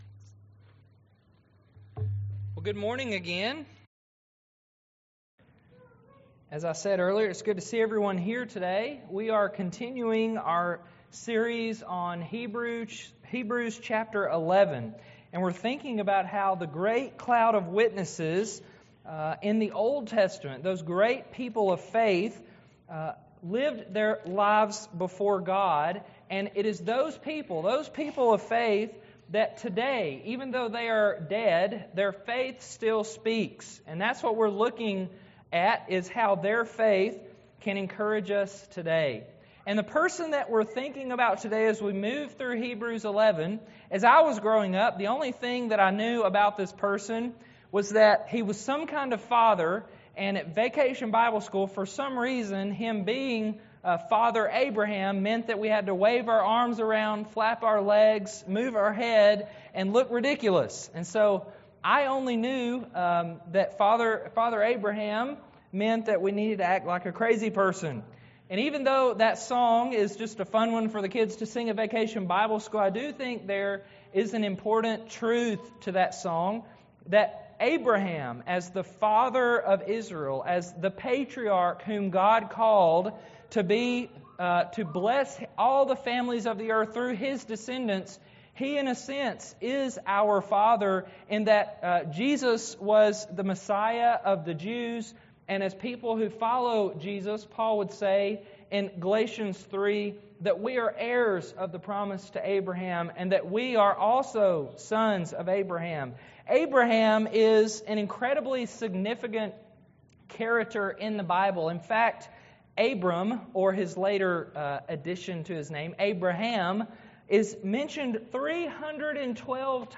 Sermon-6.4.17.mp3